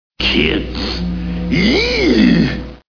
Kids eeeeEEEEeee